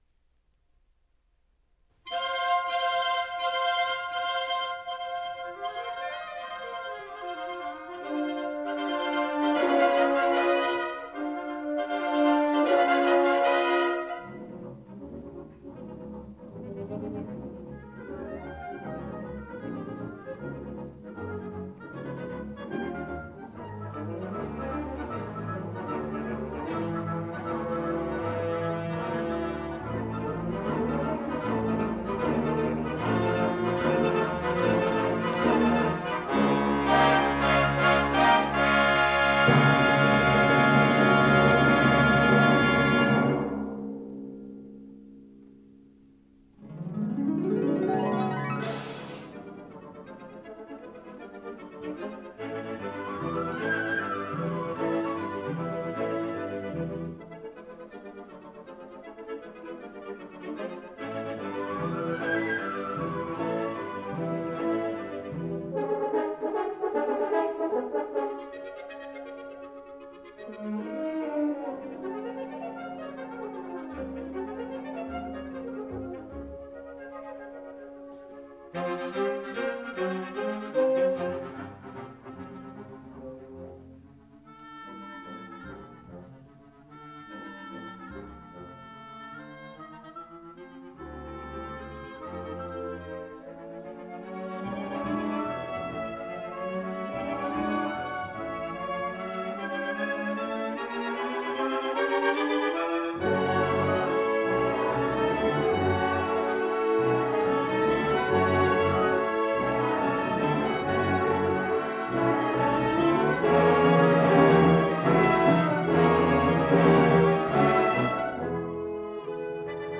１９７０年代の高等部吹奏楽団の演奏会記録です
玉川学園高等部吹奏楽団 第１０回定期演奏会
1977年3月21日 / 都市センターホール